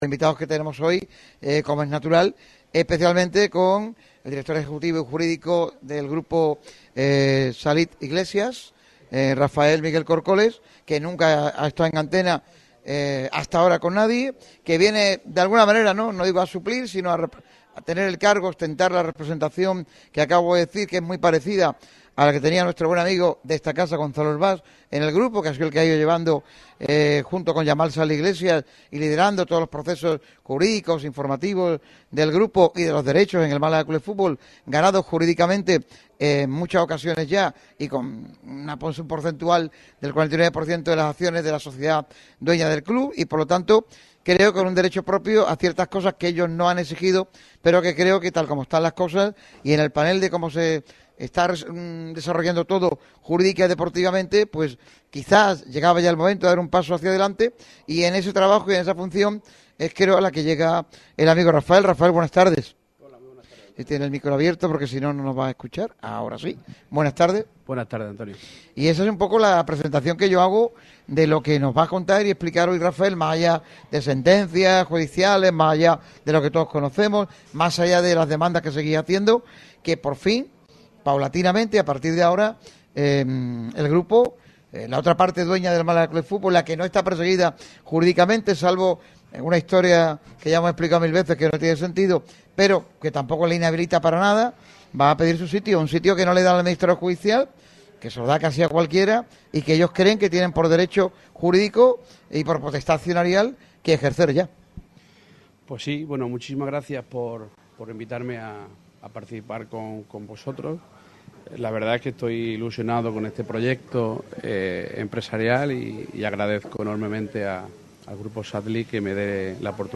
Radio MARCA Málaga ha estado presente en Asador Iñaki en un programa especial con el futuro del Málaga CF como plato protagonista.